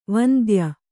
♪ vandya